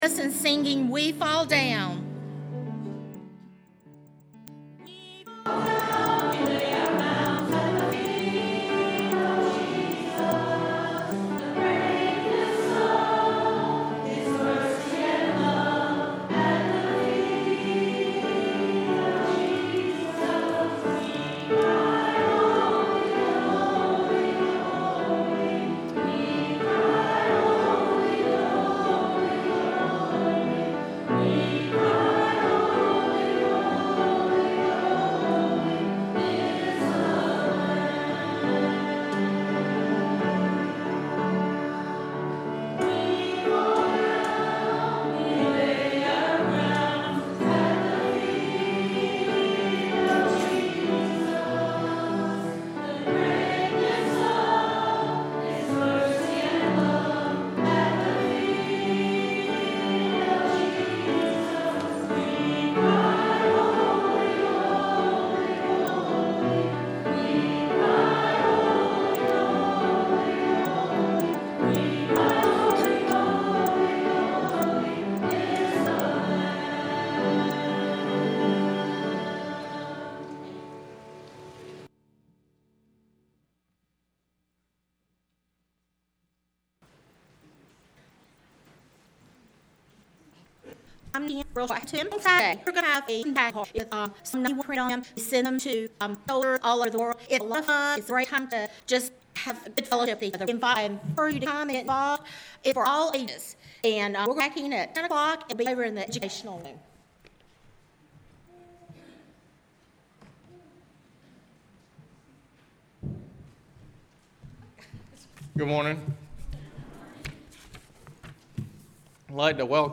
Tramway Baptist Church Sermons